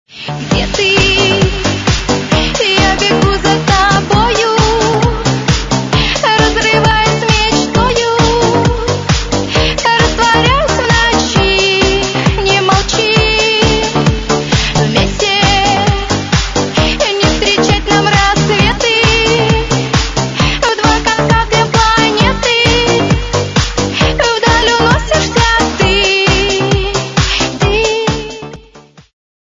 Категория: Танцевальные